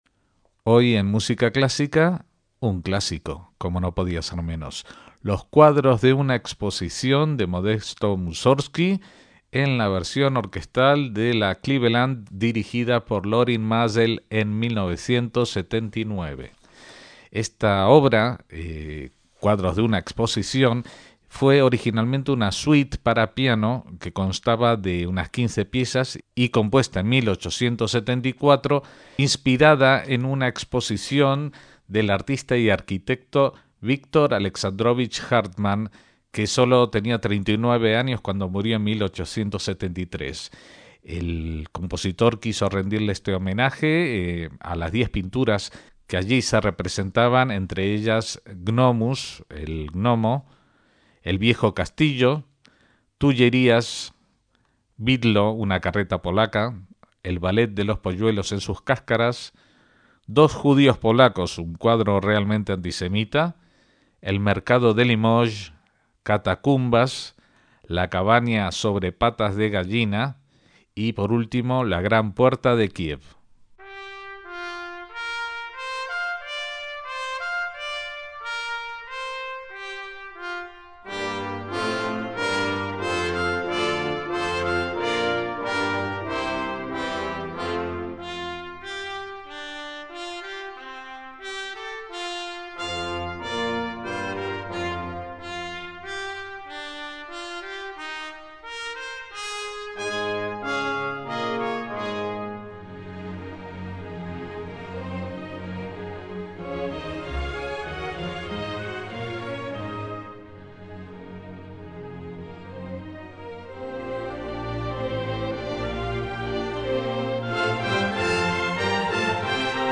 Cuadros de una exposición de Mussorgski, con la orquesta de Cleveland dirigida por Lorin Maazel
MÚSICA CLÁSICA